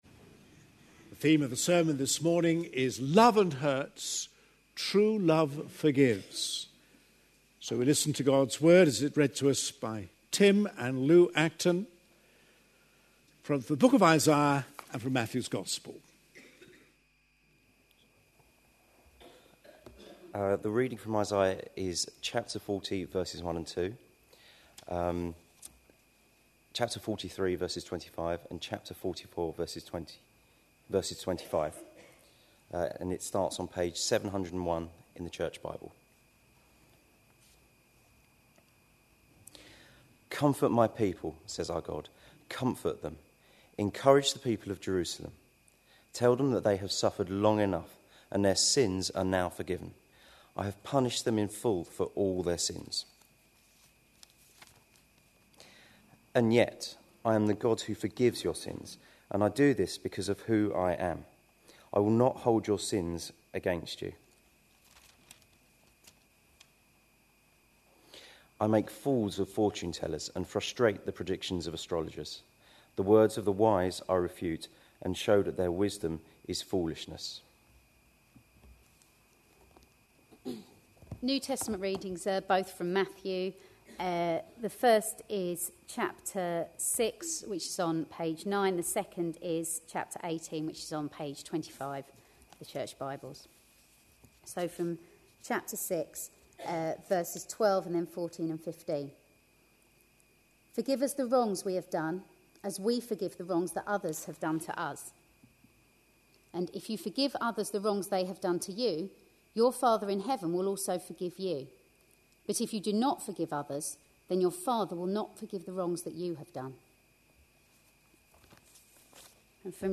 A sermon preached on 4th March, 2012, as part of our Looking For Love (10am Series) series.